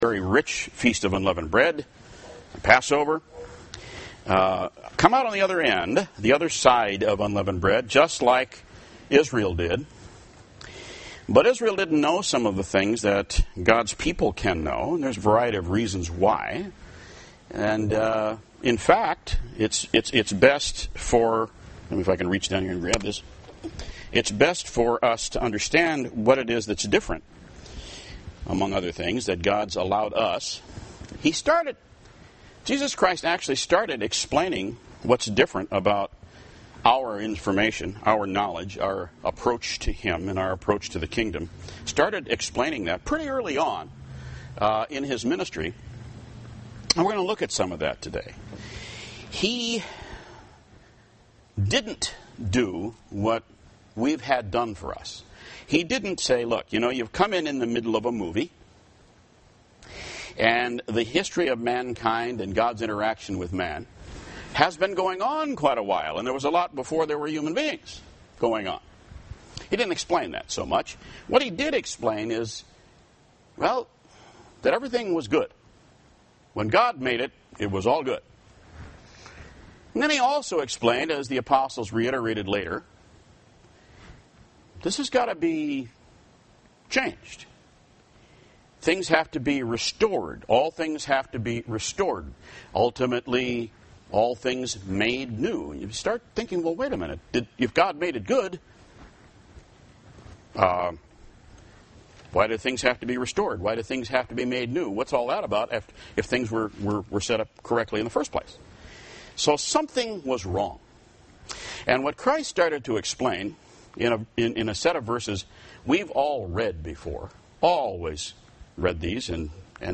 UCG Sermon Notes Introduction: Jesus Christ took the time to discuss with His disciples what is wrong with mankind.